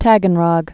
(tăgən-rŏg, tə-gən-rôk)